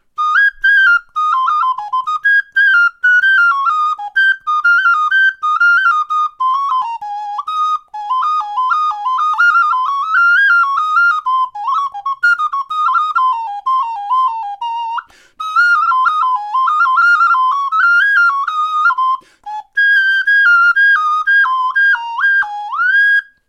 The Puny Tune is a small 4-holed whistle that can play an accurate musical scale of 13 notes.
It has also been exhibited at the Renwick Gallery of the Smithsonian Institution in Washington D.C. The Puny Tune is tuned to a precision electronic reference to assure an accurate scale.